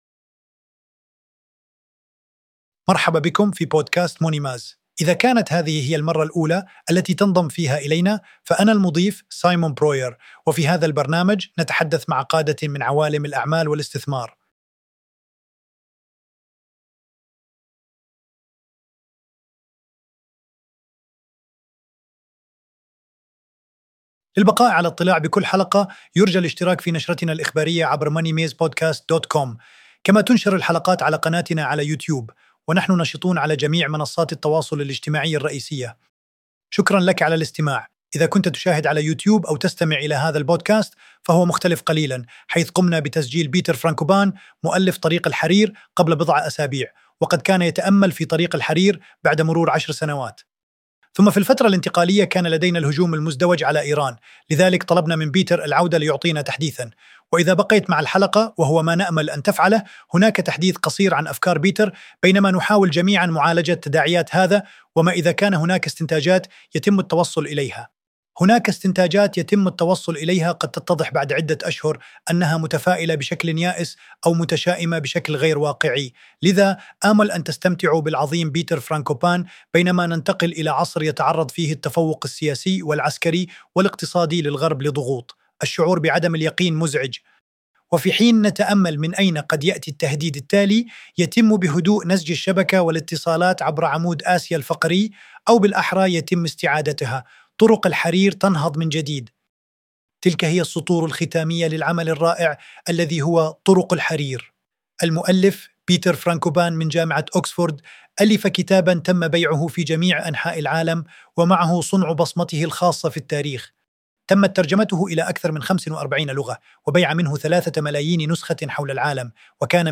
وقد صدرت النسخة المحدثة منه مؤخرًا. في هذه المحادثة، يتأمل بيتر في القوى الفاعلة داخل آسيا وخارجها. ويُقيّم النظام العالمي المتغير، ويناقش ما إذا كانت تعقيدات التفاعلات، التي قد تبدو لنا غير مريحة، أقل عنفًا ودراماتيكية عند النظر إليها من منظور تاريخي.